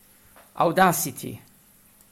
Nel testo compare anche un link ad un minuscolo file audio, nel quale pronuncio semplicemente la parola “audacity”. Serve a verificare personalmente una questione inerente all’impiego dei decibel.